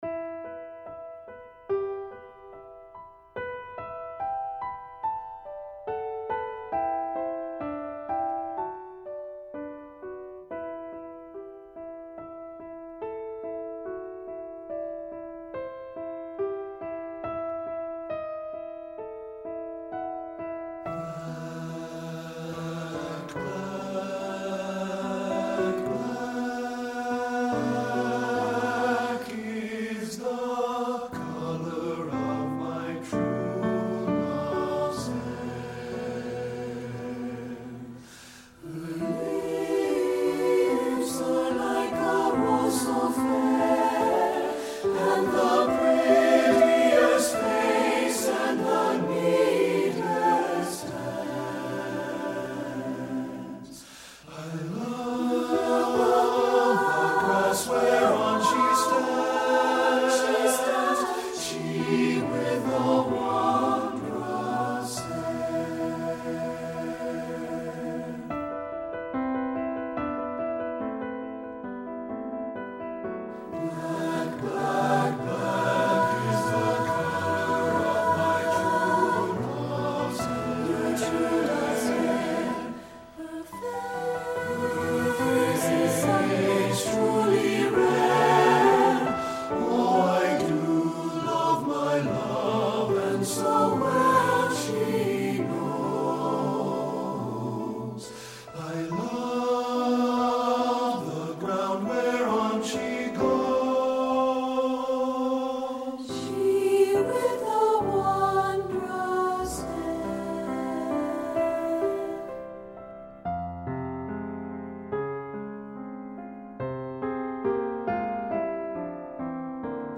Composer: Appalachian Folk Song
Voicing: SATB